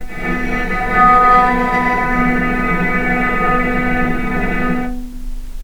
Strings / cello / sul-ponticello
vc_sp-B3-pp.AIF